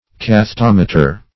Cathetometer \Cath`e*tom"e*ter\, n. [From Gr.